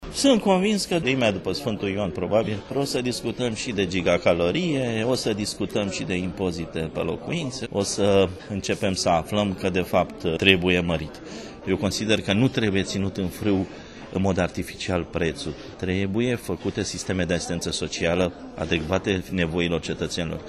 AUDIO: interviu